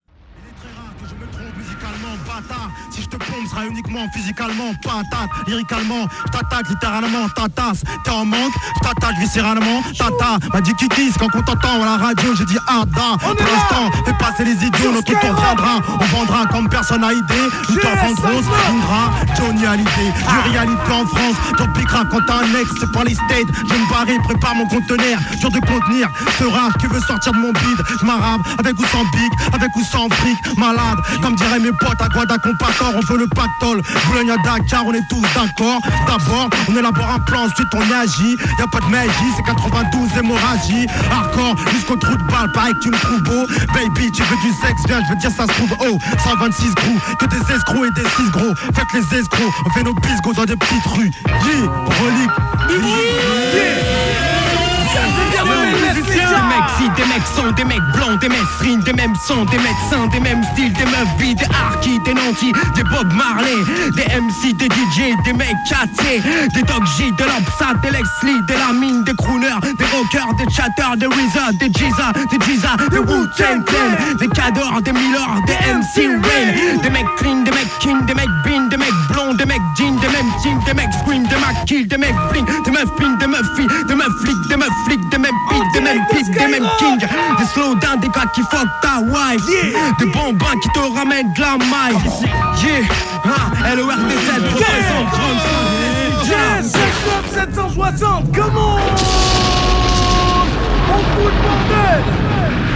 J’arrive au studio, il y a 80 personnes dans la pièce, je ne connais personne à part les mecs de Relic, qui forcément sont dans leur Planète Rap et n’ont pas le temps de me calculer.
Jusque-là, tous avaient rappé de la même manière, moi je lâche un freestyle qui n’a rien à voir, tout saccadé.
Freestyle au Planète Rap de Relic